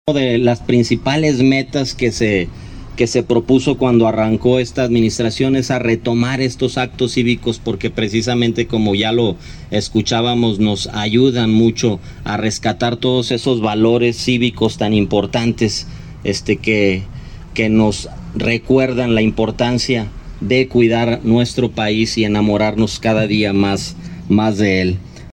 Irapuato, Gto. 10 de junio del 2024 .- Para que jóvenes estudiantes conozcan más sobre los valores cívicos y las efemérides más importantes de este mes, se llevó a cabo el izamiento de la bandera monumental.
En esta ocasión, el presidente municipal interino, Rodolfo Gómez Cervantes, habló a jóvenes del CBTis 65, sobre la importancia de participar en la democracia y en la transformación de nuestro país, al ser ciudadanos con valores.
Rodolfo Gómez Cervantes, presidente interino